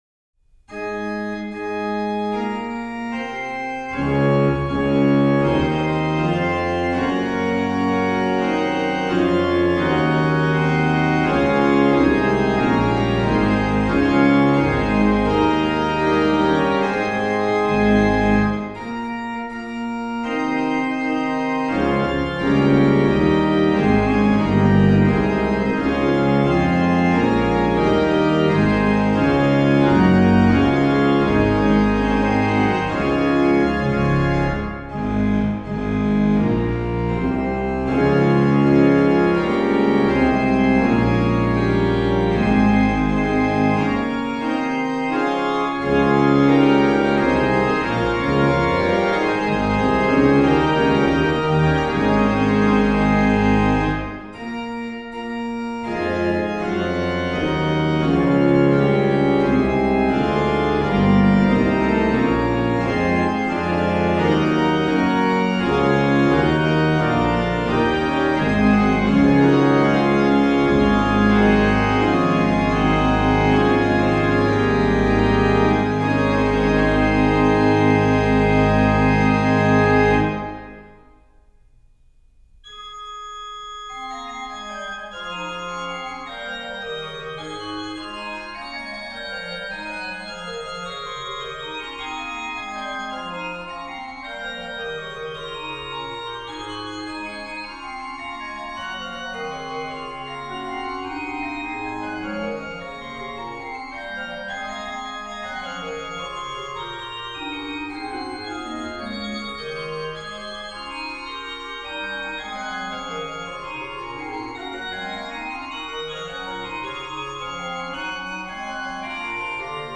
Subtitle   Alla breve
MAN: Qnt16, Pr8, Bor8, Oct4, Qnt3, Oct2, Mix
PED: Viol16, Oct8, Pos16, MAN/PED
MAN: Qnt16, Gms4, Oct2 (8ve higher)
MAN: Viol8, Bor8
MAN: Pr8, Bor8, Oct4, Qnt3, Oct2
PED: Sub16, Oct8, Oct4, Tr8